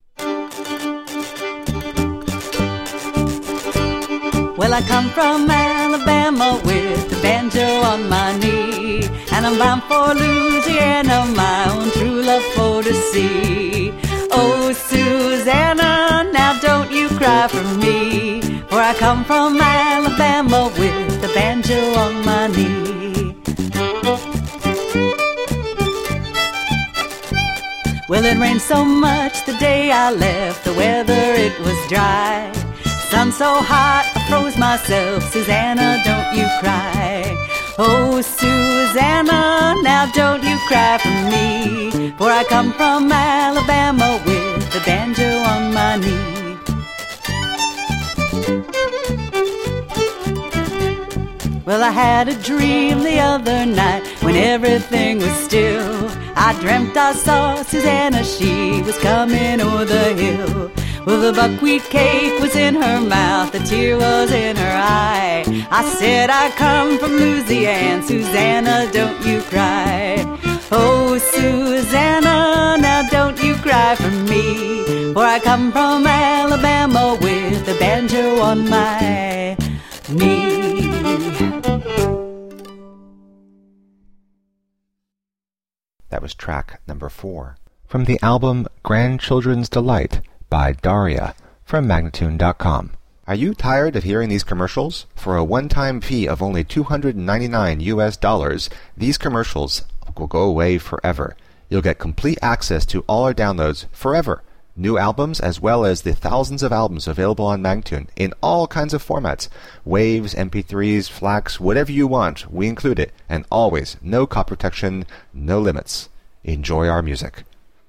Tagged as: World, Children, Folk